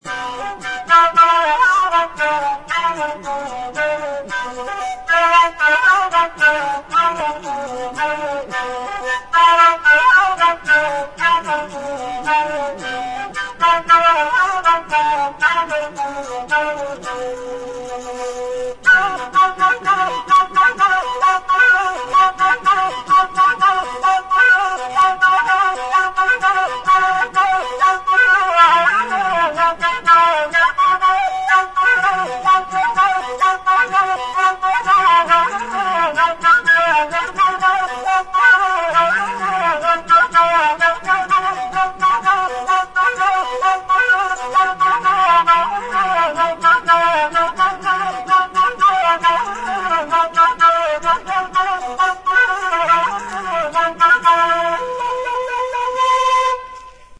DILLI KAVAL; DÜDÜK | Soinuenea Herri Musikaren Txokoa
TWO FLUTE TUNES FOR DILLI KAVAL.
Bi eskuko flauta zuzena da. 7 zulo ditu (6 aurrekaldean eta atzekaldean).
Aérophones -> Flûtes -> Á bec (á deux mains) + kena Emplacement